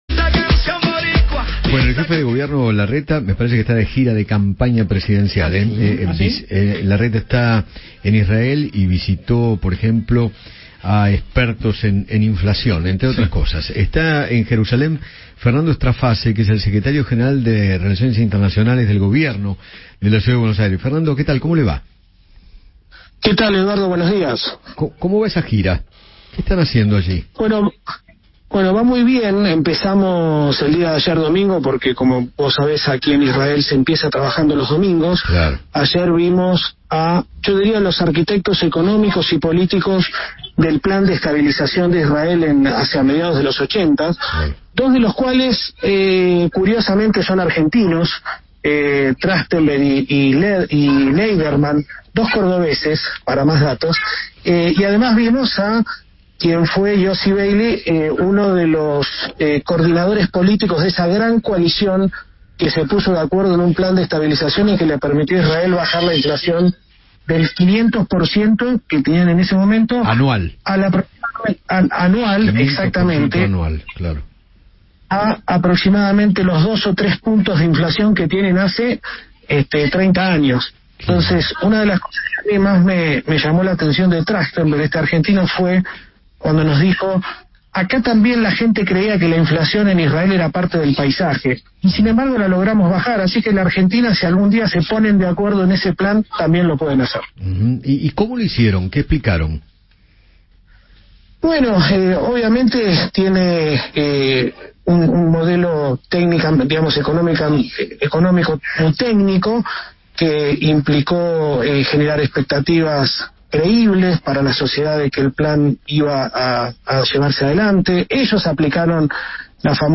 Fernando Straface, secretario general de Relaciones Internacionales de CABA, charló con Eduardo Feinmann sobre la gira de Horacio Rodríguez Larreta por España e Israel enfocada en reuniones con expertos en economía y referentes del sector público y privado en materia de innovación.